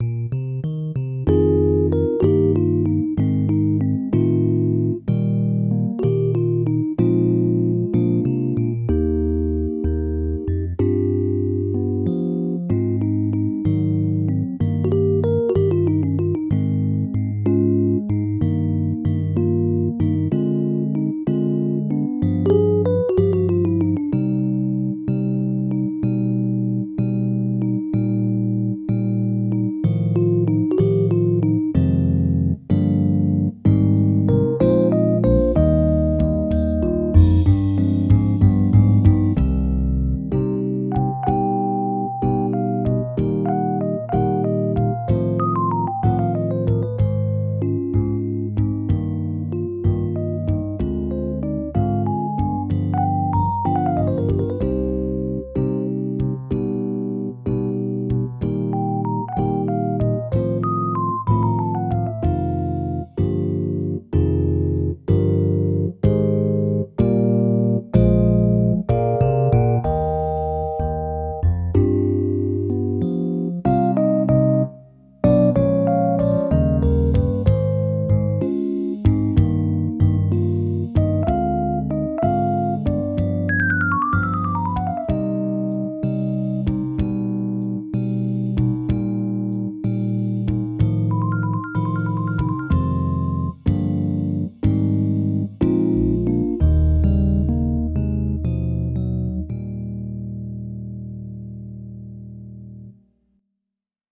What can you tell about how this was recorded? sw121-19_Audition_orch.mp3